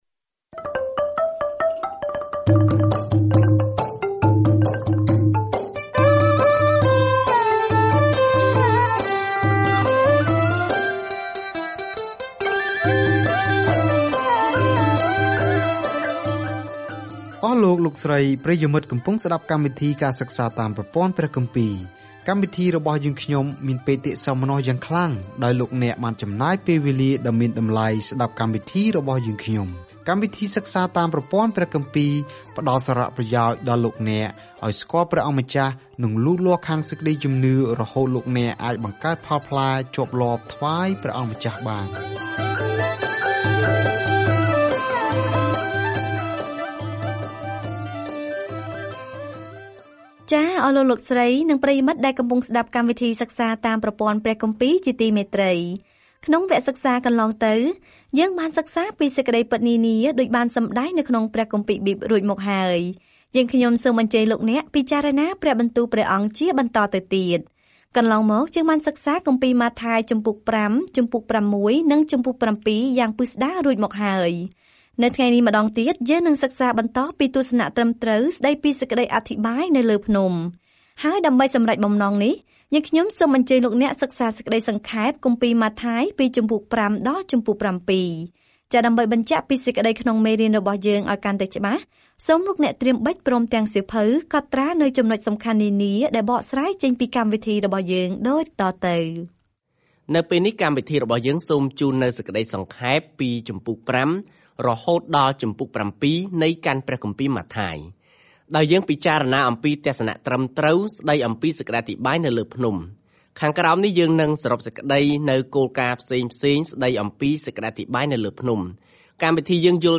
ម៉ាថាយបង្ហាញដល់អ្នកអានសាសន៍យូដានូវដំណឹងល្អថា ព្រះយេស៊ូវគឺជាព្រះមេស្ស៊ីរបស់ពួកគេ ដោយបង្ហាញពីរបៀបដែលជីវិត និងកិច្ចបម្រើរបស់ទ្រង់បានបំពេញតាមទំនាយក្នុងព្រះគម្ពីរសញ្ញាចាស់ ។ ការធ្វើដំណើរប្រចាំថ្ងៃតាមម៉ាថាយ ពេលអ្នកស្តាប់ការសិក្សាជាសំឡេង ហើយអានខគម្ពីរដែលជ្រើសរើសពីព្រះបន្ទូលរបស់ព្រះ។